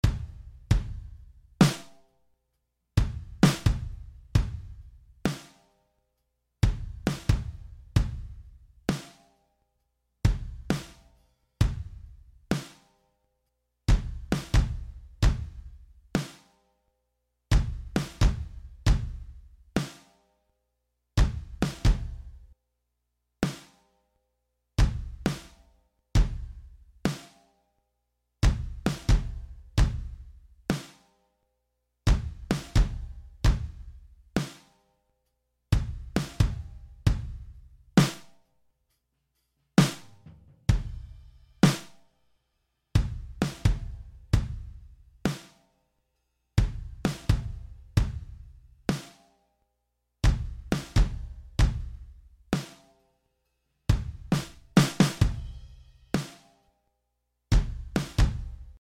Save this secret snare and kick setting that adds punch and snap to your drums in seconds.